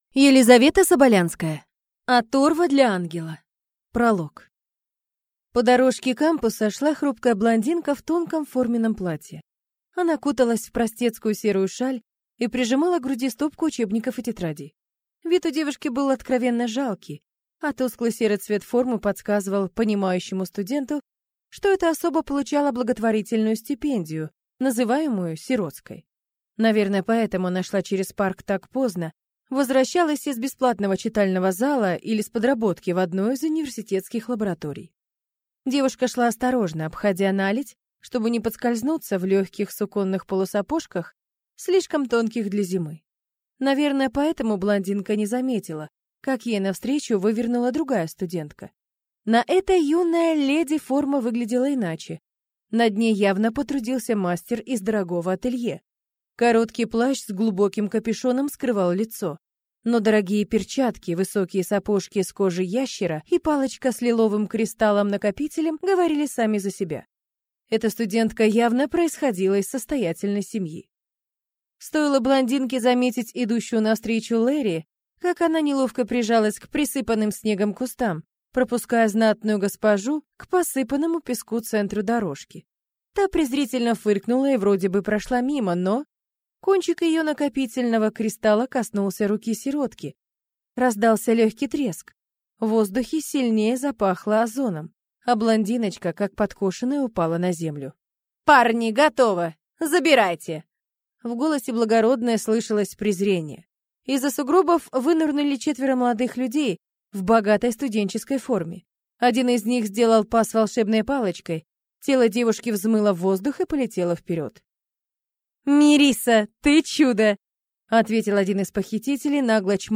Аудиокнига Оторва для Ангела | Библиотека аудиокниг